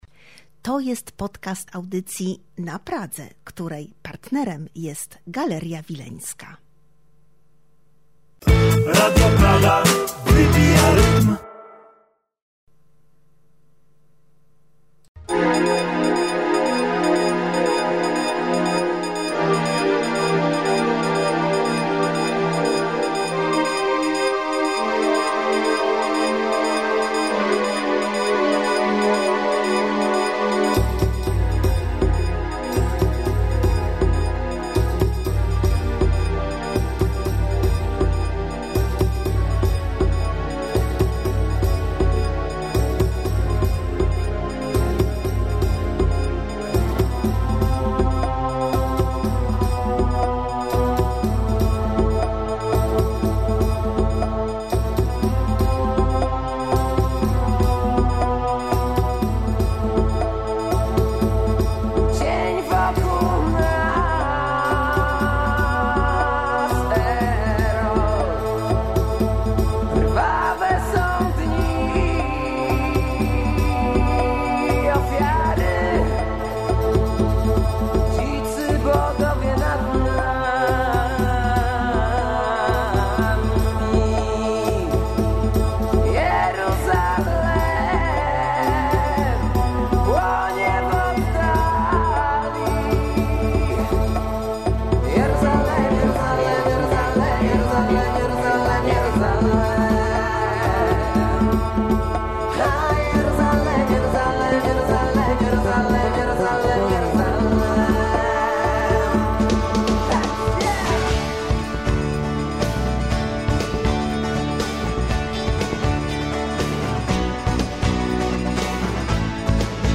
Zapis tej rozmowy na końcu artykułu.